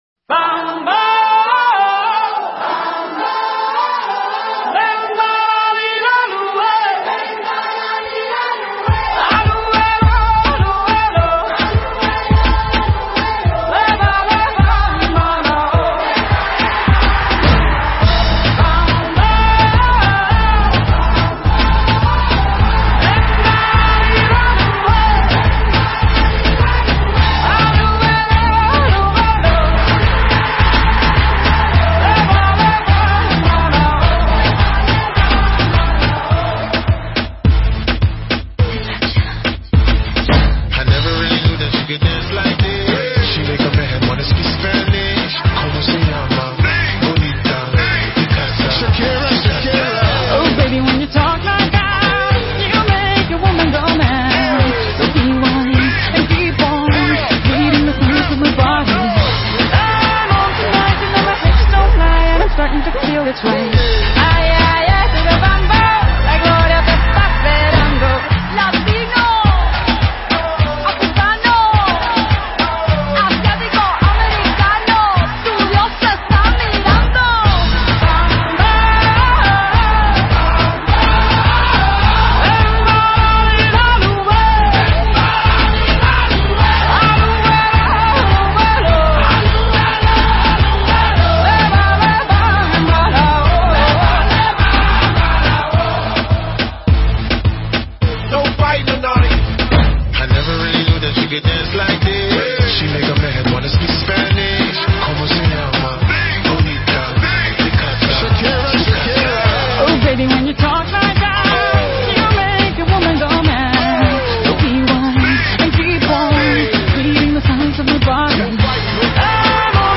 Nhạc Latin